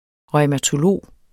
Udtale [ ʁʌjmatoˈloˀ ]